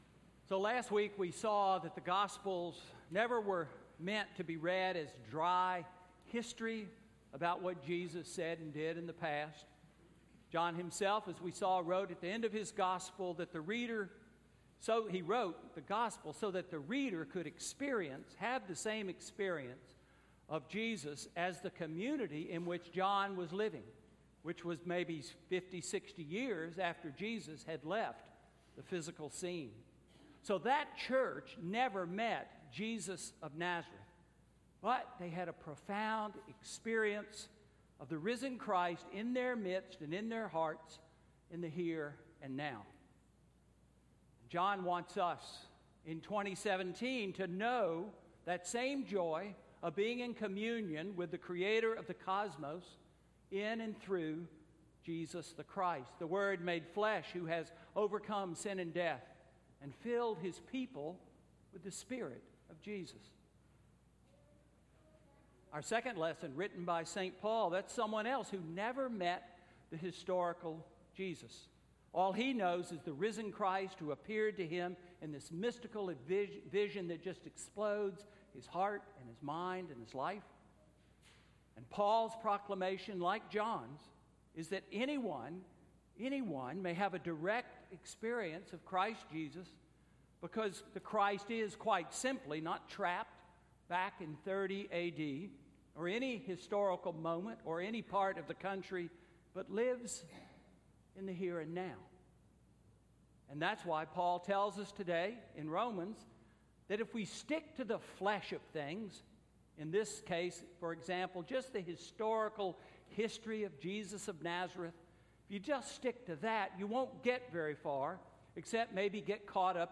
Sermon–April 2, 2017